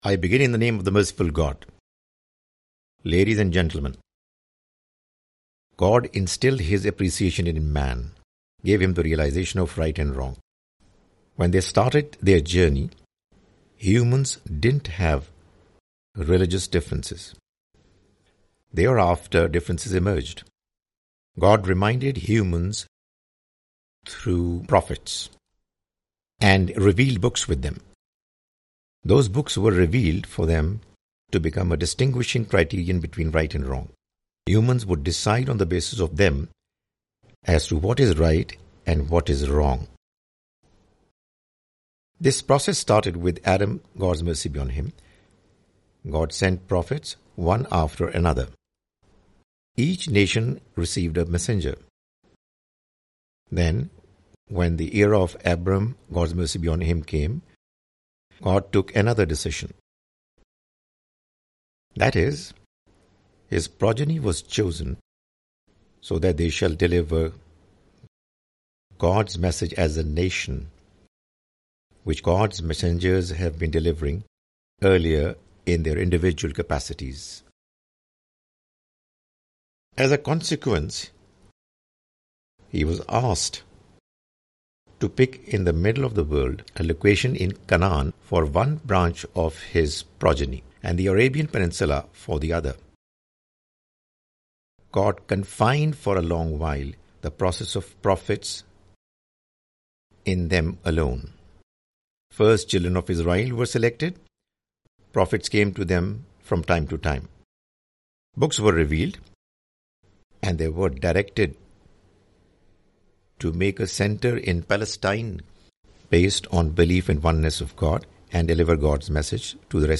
The Message of Qur'an (With English Voice Over) Part-5
The Message of the Quran is a lecture series comprising Urdu lectures of Mr Javed Ahmad Ghamidi.